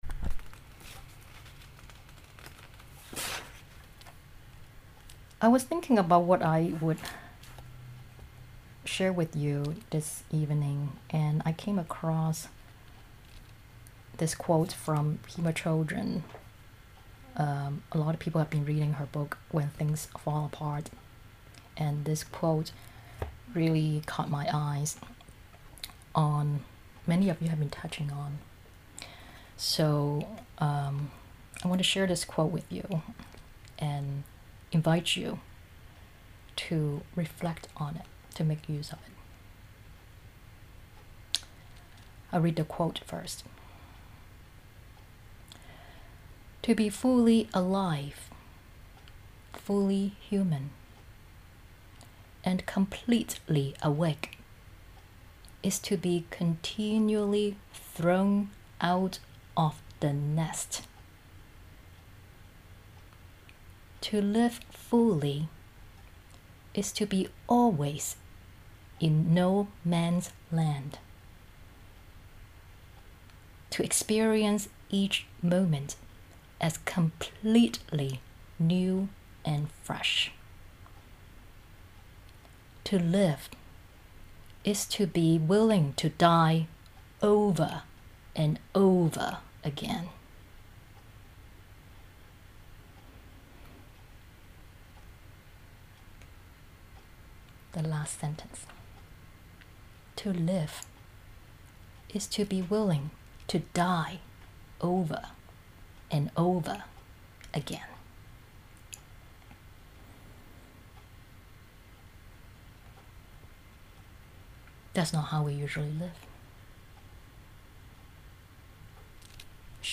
This talk was given in the Weekly Online Dharma Practice Gathering on May 29, 2020.